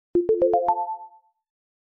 点击2.wav